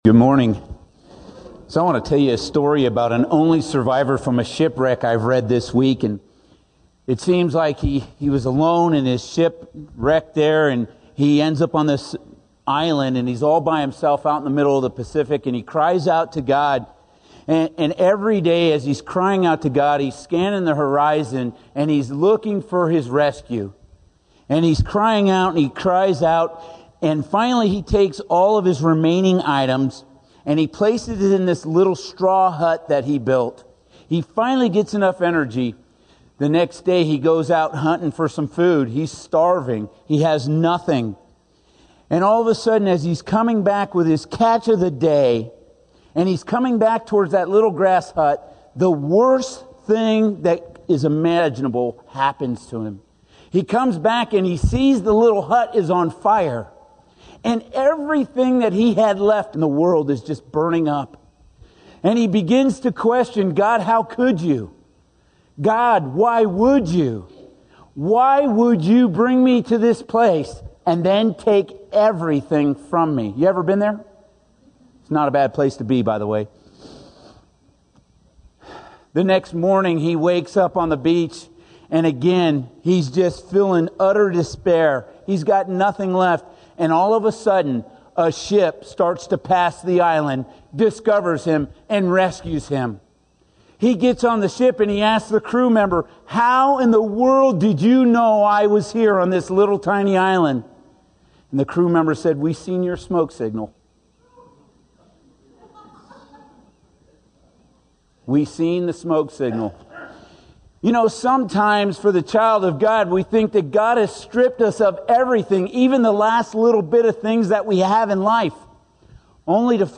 - Ruth 1:22 - 2:1-23 | Living Faith Fellowship - Klamath Falls, OR